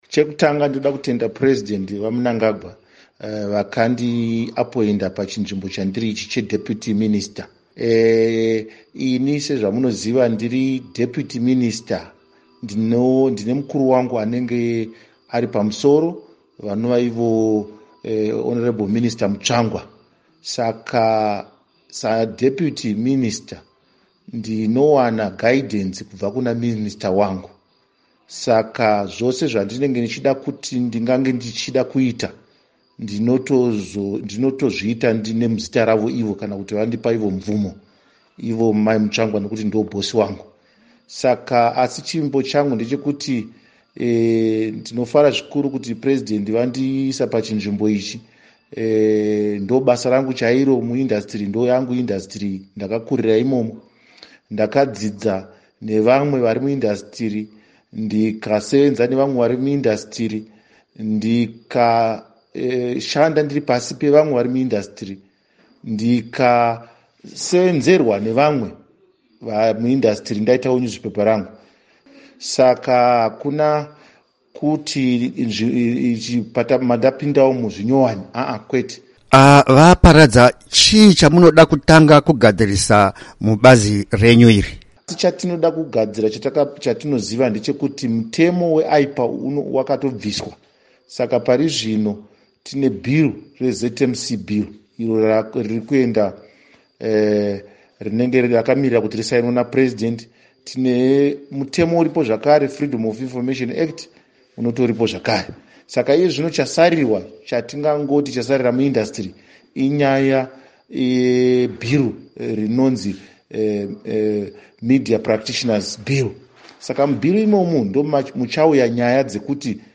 Hurukuro naVaKindness Paradza